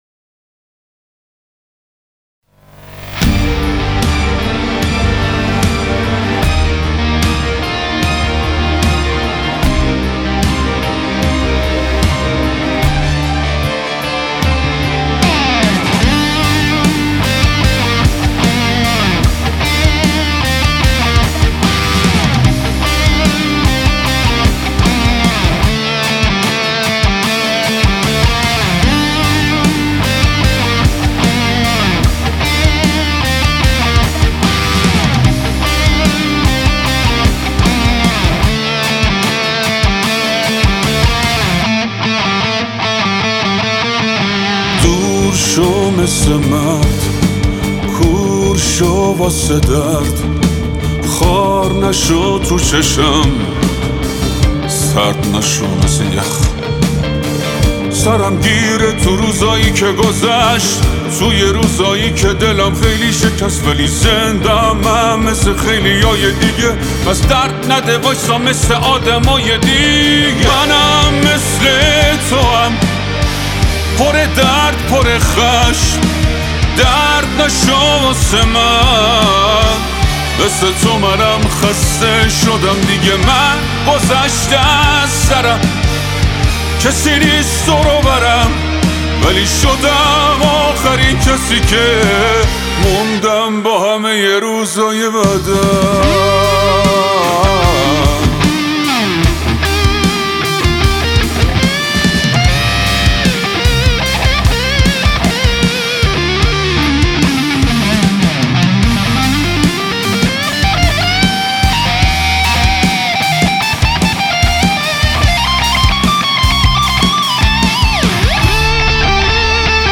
قطعه‌ای با فضایی مدرن و احساسی در سبک پاپ-راک تلفیقی
لید گیتار
ریتم گیتار
درامز
باس‌گیتار